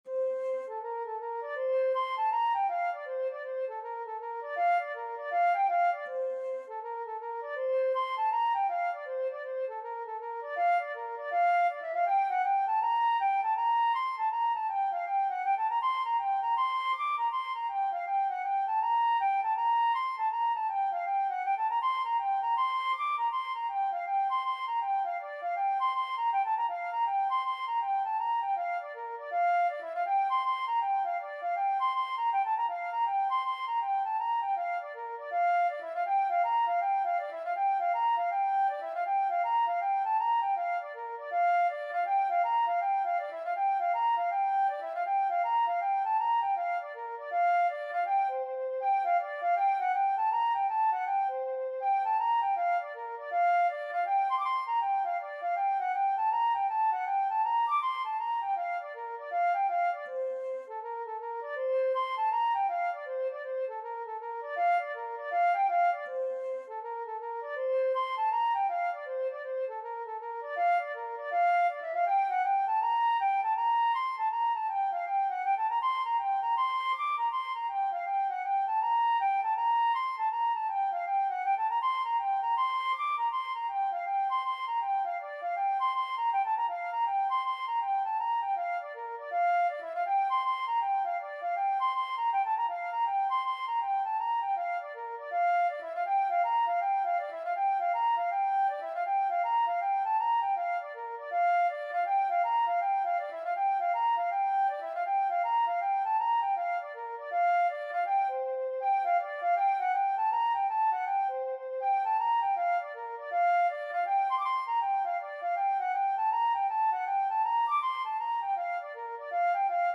Free Sheet music for Flute
F major (Sounding Pitch) (View more F major Music for Flute )
4/4 (View more 4/4 Music)
Flute  (View more Easy Flute Music)
Traditional (View more Traditional Flute Music)